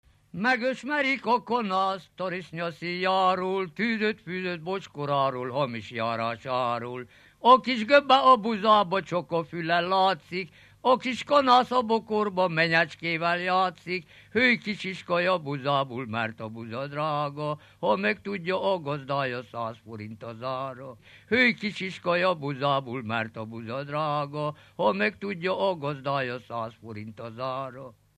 Dunántúl - Baranya vm. - Várdaróc
Stílus: 1.1. Ereszkedő kvintváltó pentaton dallamok
Kadencia: 5 (5) b3 1